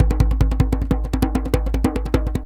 DJEM.GRV06.wav